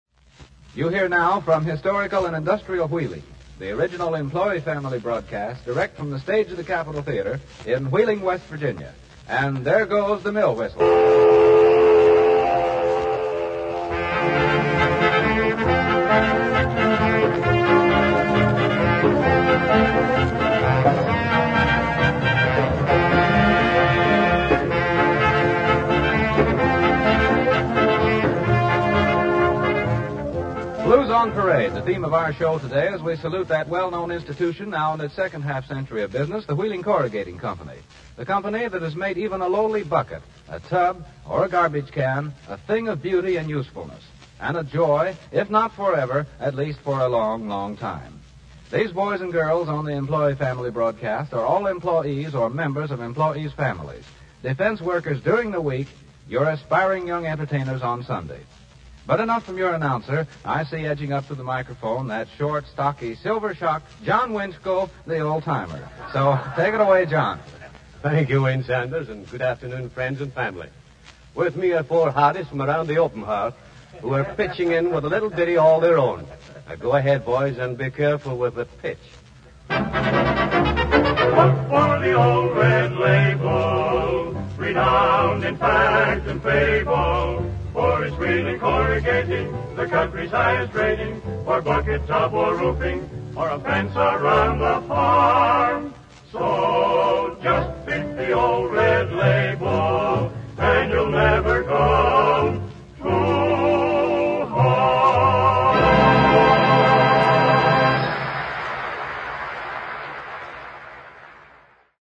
Sample sound files from the radio program:
"It's Wheeling Steel" program introduction with Red Label advertisement , 1:51 seconds.